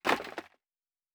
Plastic Foley Impact 4.wav